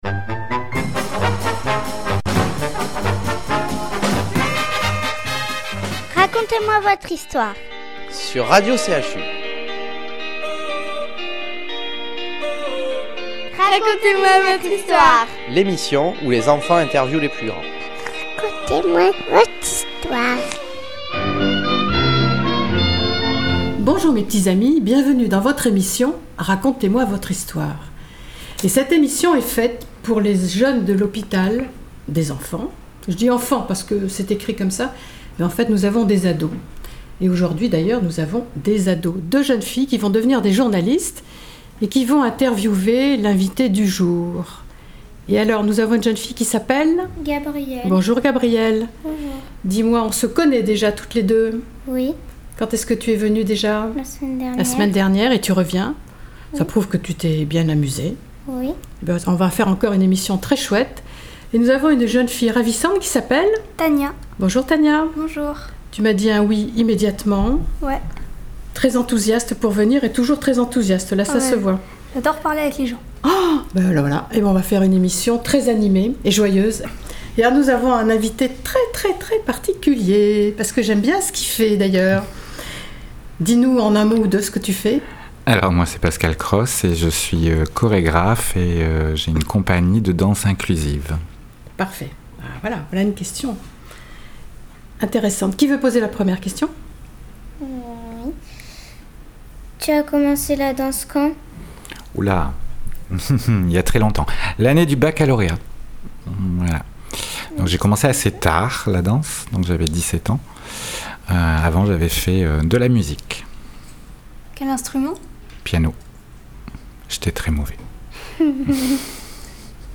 Interview
Ecoutez l’émission de radio CHU…